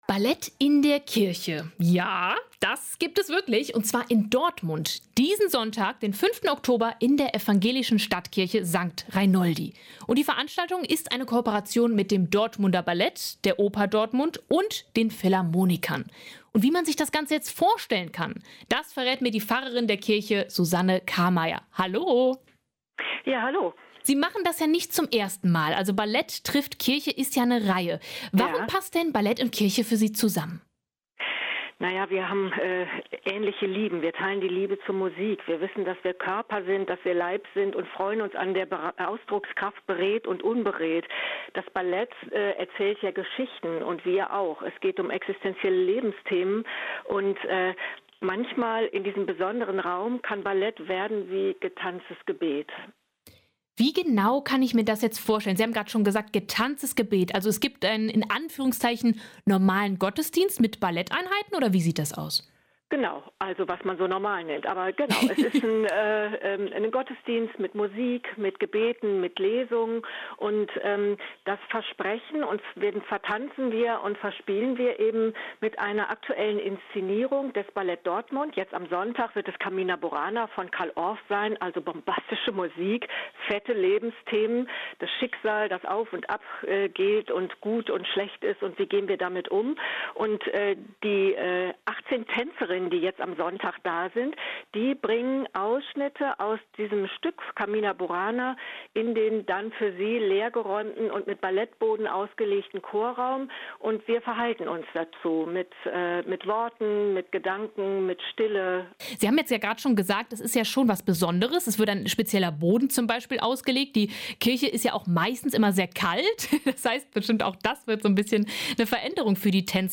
Im Interview erklärt sie, warum der Kirchraum mehr zulässt, als viele denken.